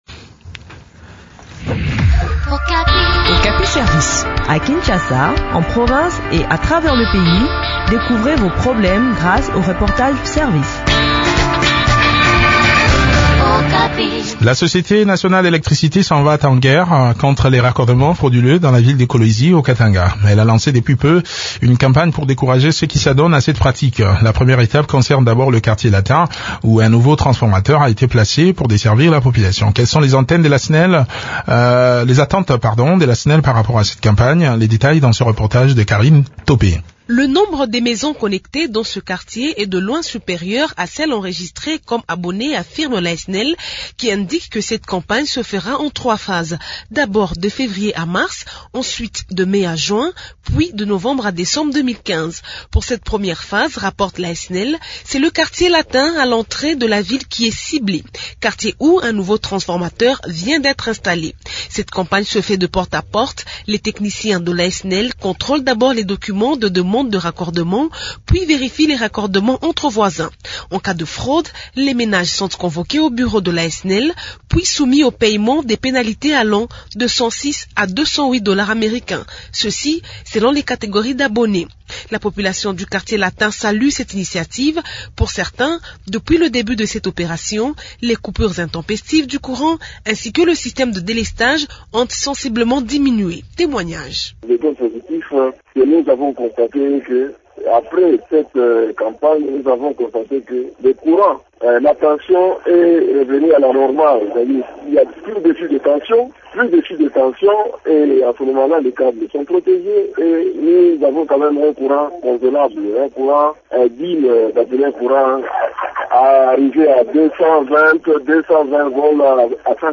Le point sur le déroulement de cette campagne dans cet entretien